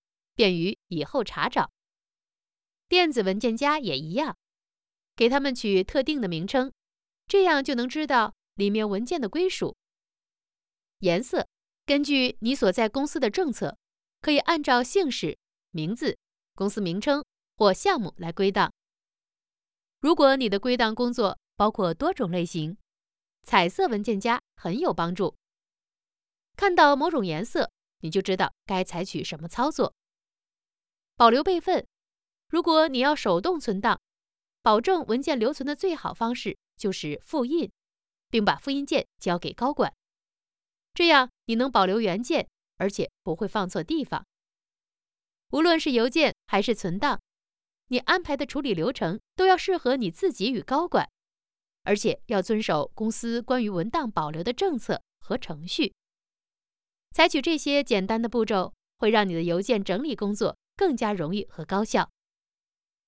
Chinese_Female_044VoiceArtist_2Hours_High_Quality_Voice_Dataset